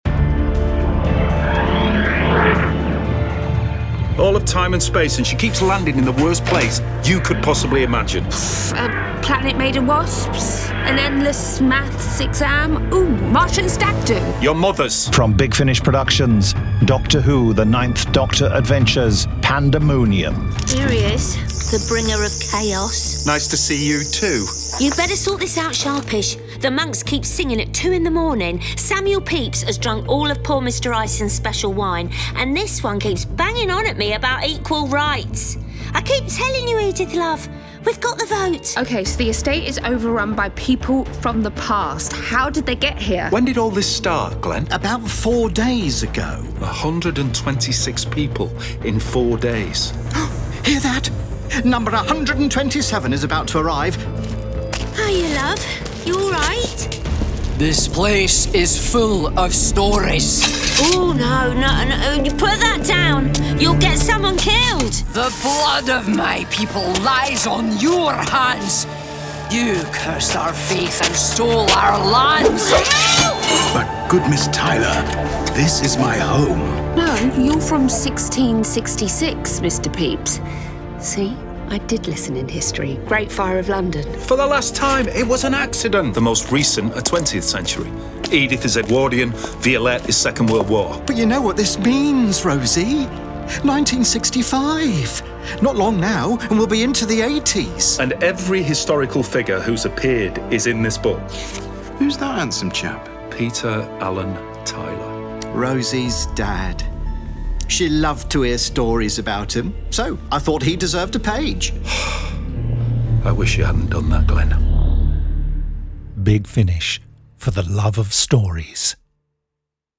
Starring Christopher Eccleston Billie Piper